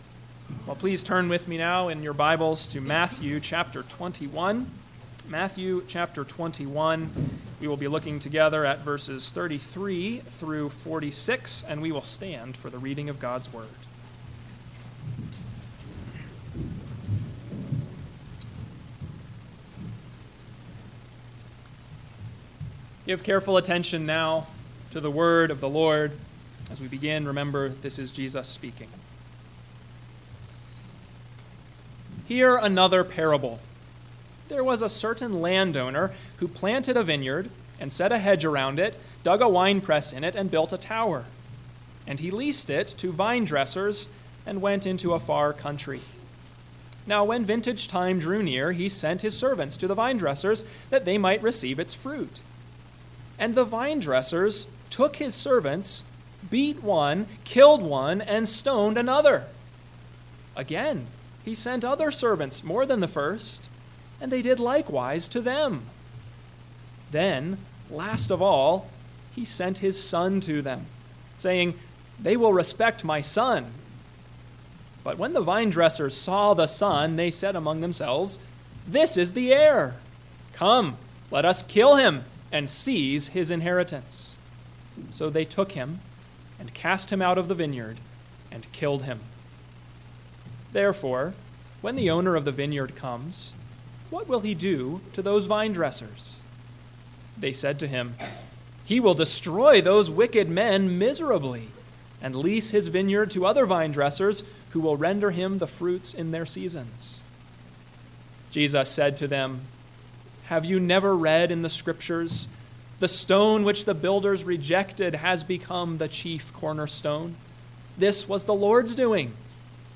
AM Sermon – 9/22/2024 – Matthew 21:33-45 – Northwoods Sermons